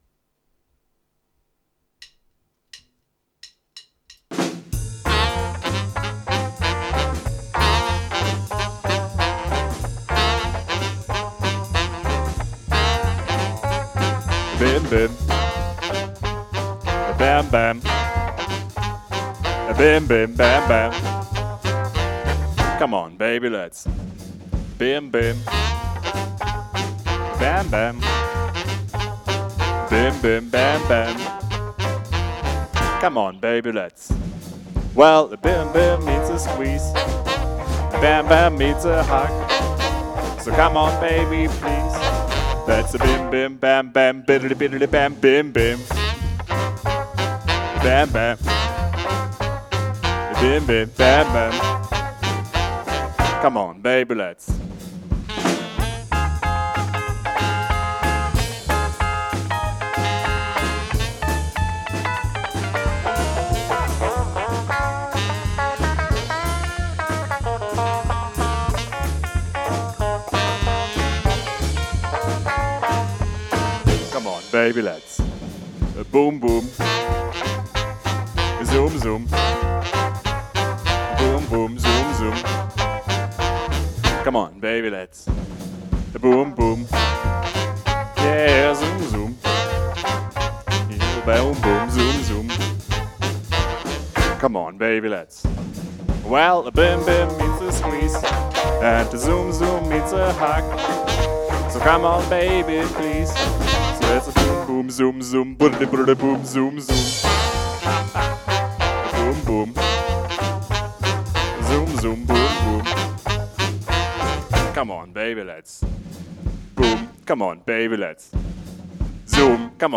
Rock 'n' Roll im Stil der 1950er
Kontrabass
Gitarre
Schlagzeug
Piano
Saxophon